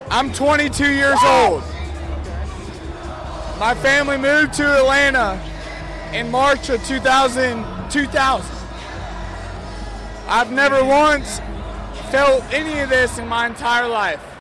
Listen to the fans of the Braves and their reactions to winning the World Series: